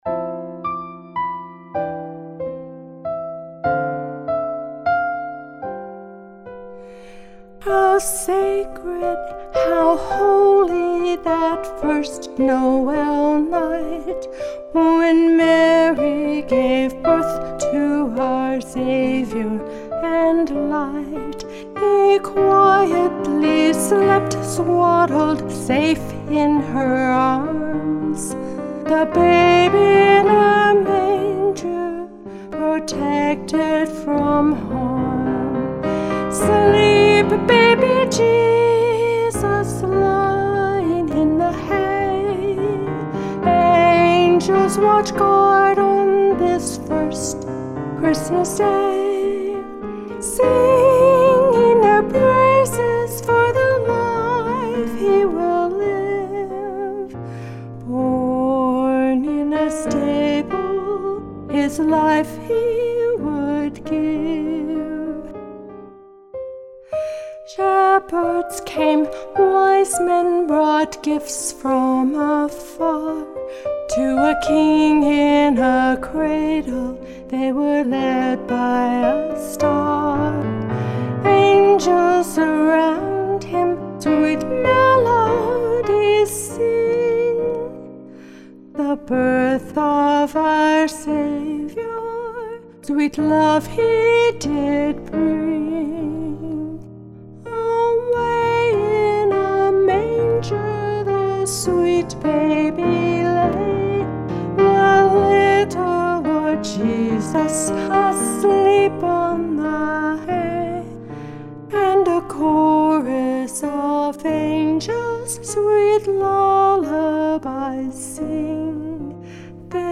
The last two years I’ve felt impressed that it wanted to be a vocal solo, and have toyed with that.